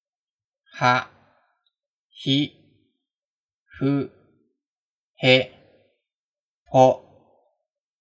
は行 a i u e o